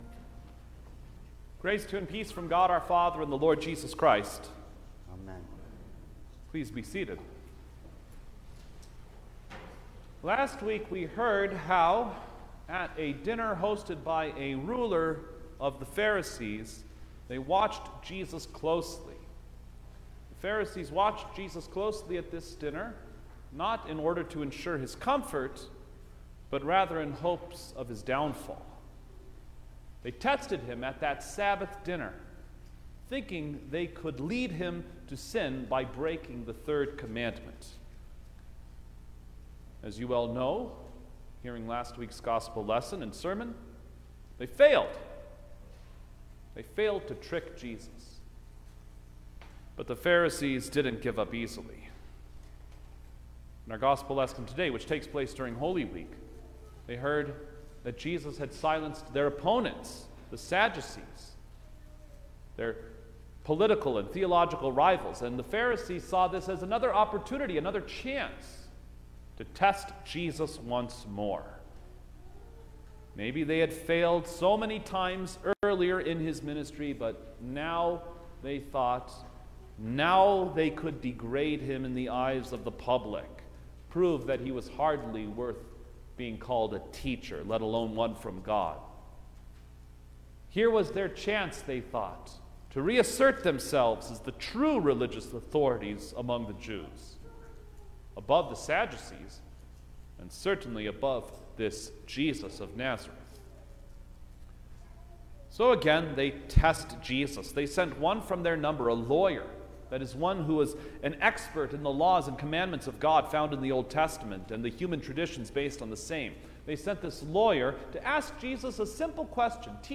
October-3_2021_Eighteenth-Sunday-After-Trinity_Sermon-Stereo.mp3